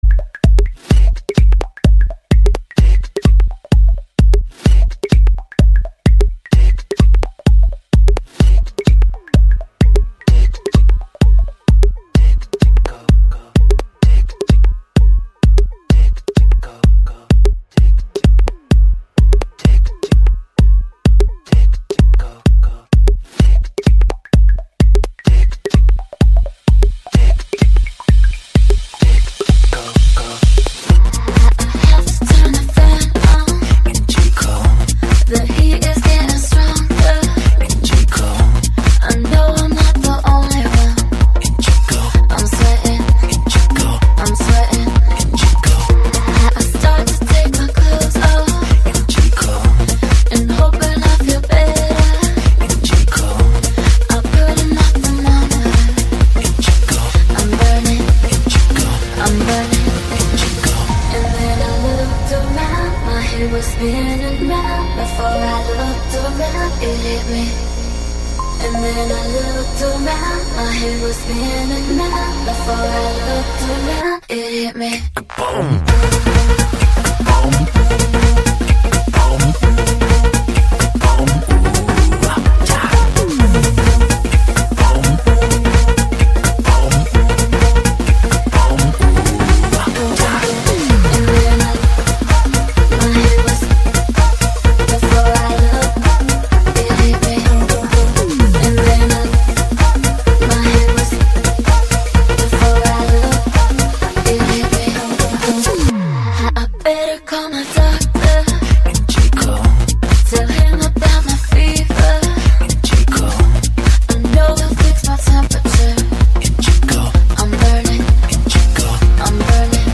Extended Version
Стиль: Progressive House